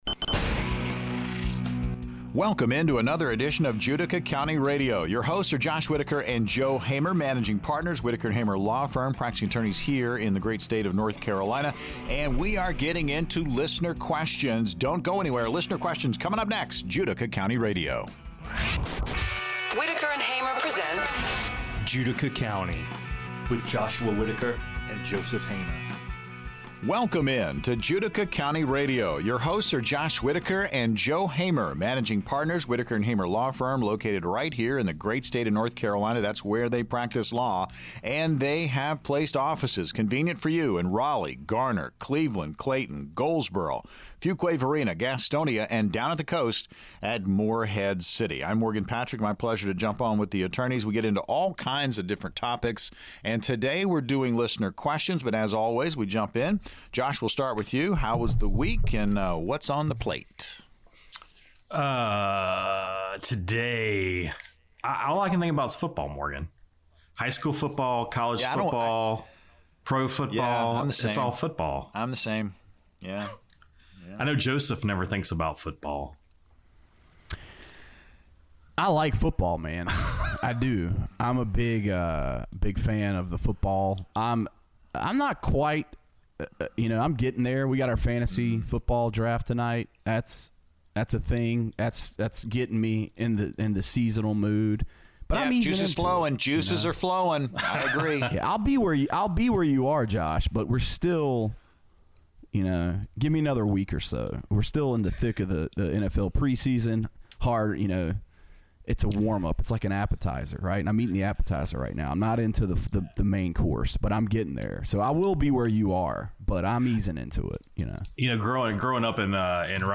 They discuss the importance of consulting an attorney before paying traffic tickets, the complexities of personal injury cases, and the differences between various types of property deeds, including warranty and quit claim deeds. The conversation is light-hearted yet informative, providing valuable insights into legal processes and the importance of proper legal guidance.